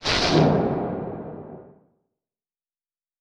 Magic Demo